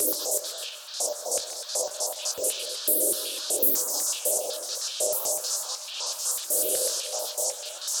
Index of /musicradar/stereo-toolkit-samples/Tempo Loops/120bpm
STK_MovingNoiseD-120_02.wav